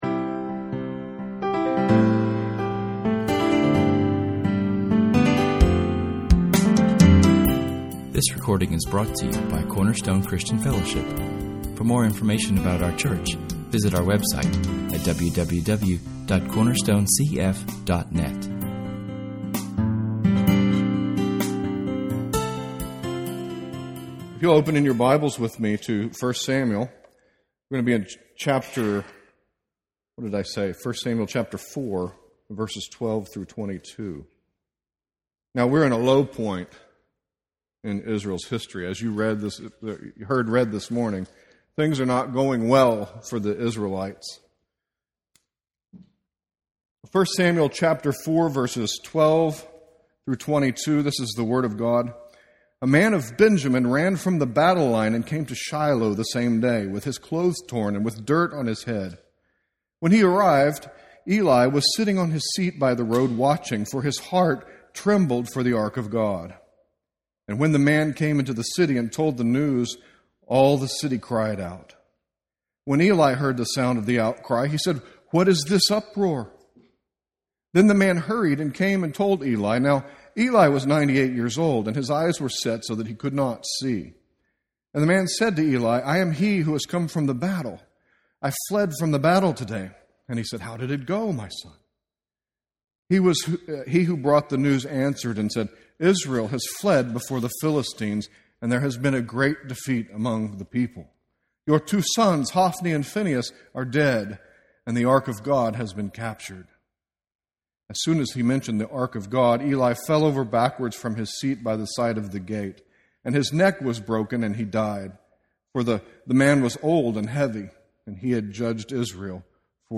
This sermon, from [esvignore]1 Samuel 4:12-22[/esvignore], examines several themes that are present in the terrible days of Israel when the Ark was captured by the Philistines. First we observe the story of Eli’s death.